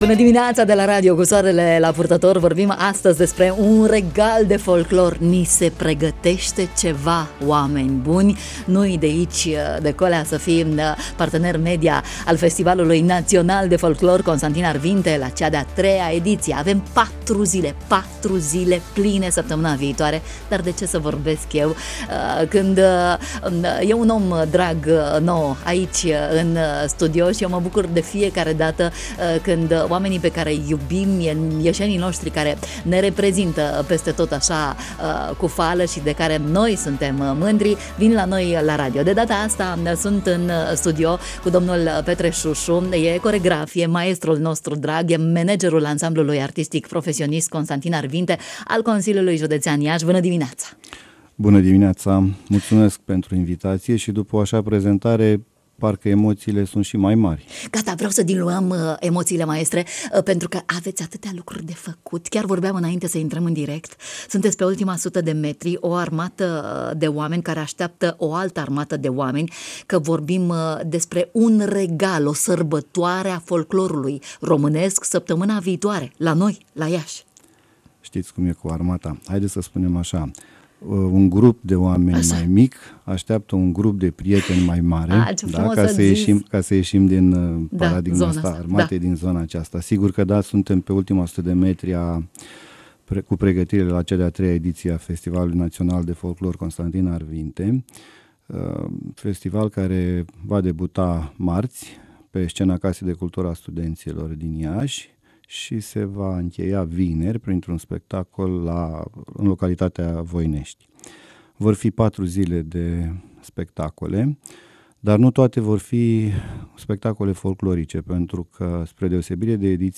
În studio, în direct